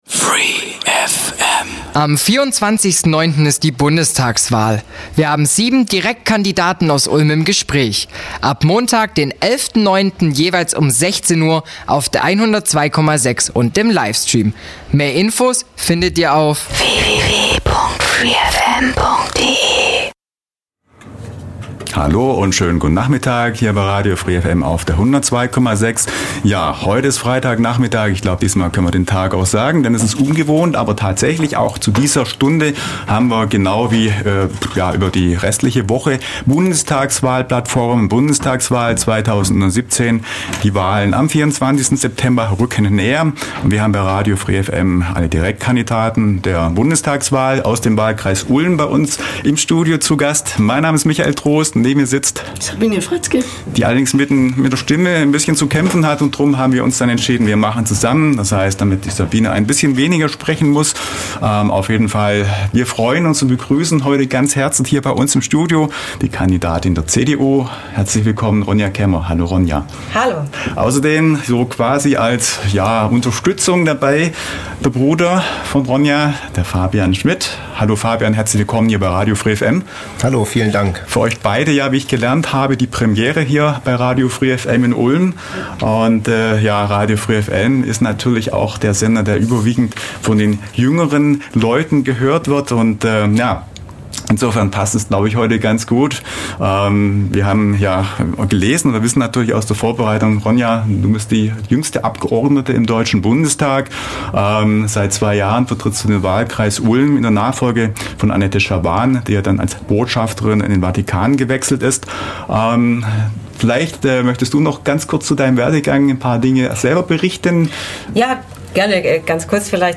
Eine Sondersendereihe zur Bundestagswahl hört ihr zwischen dem 11. und dem 19. September auf dem Sendeplatz der Plattform. Wir haben die Direktkandidaten zum Gespräch eingeladen.